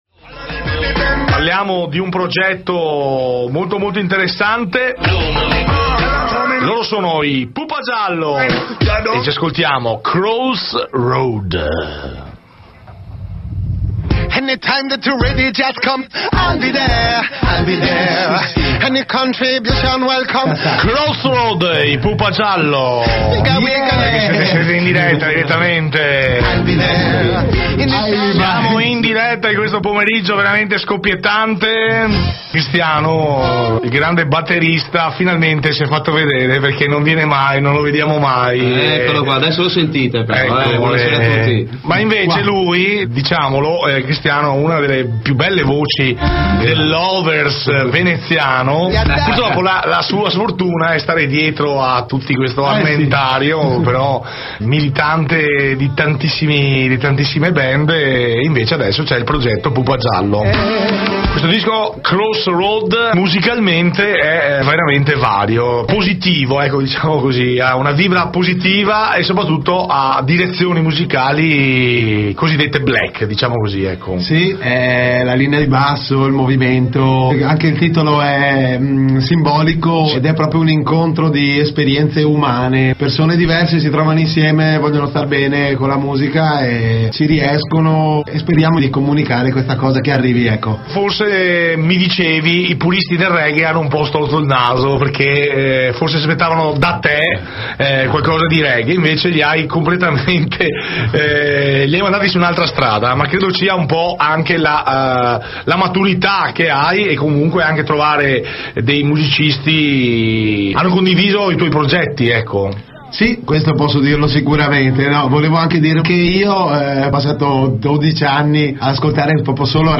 Intervista Radio Base